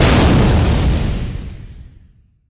054-Cannon03.mp3